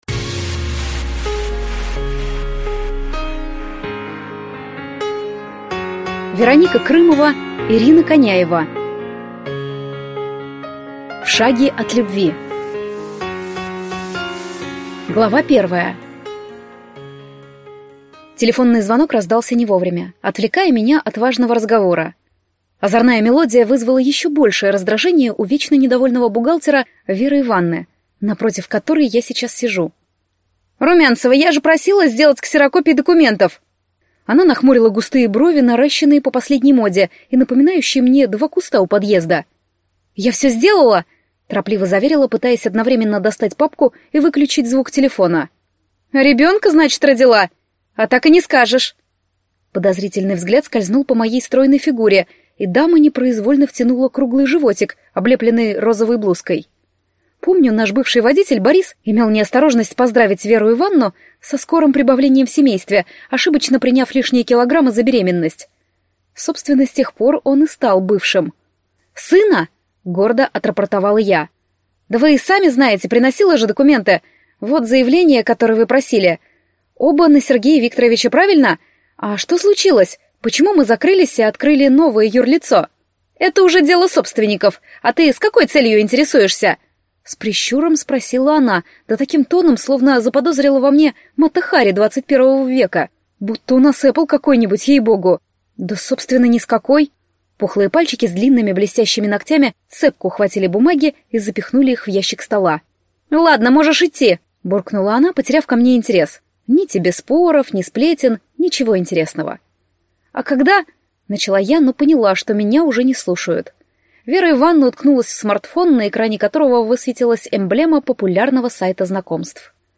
Аудиокнига В шаге от любви | Библиотека аудиокниг